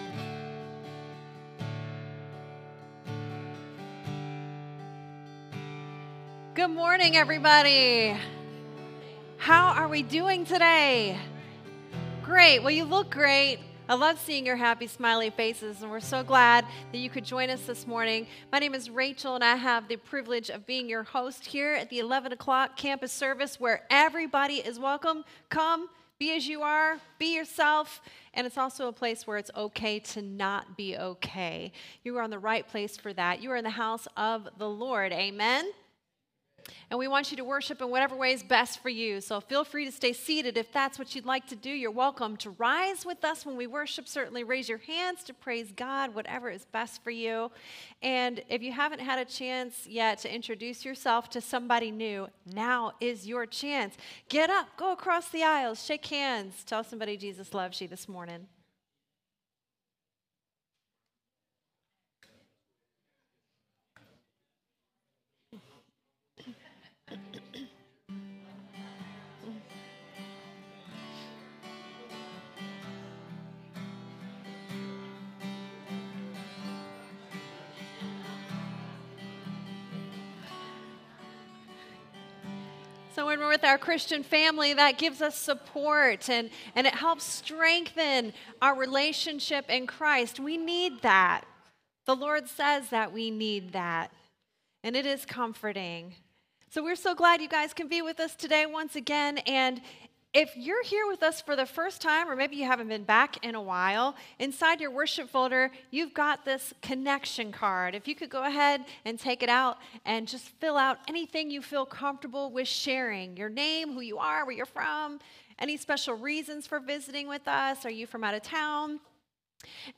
SERMON DESCRIPTION We are told to continually pray for others who are experiencing personal struggles or are in difficult circumstances.